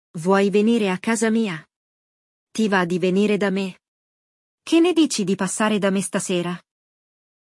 No episódio de hoje, vamos acompanhar um diálogo entre duas amigas organizando seus planos para o sábado à noite.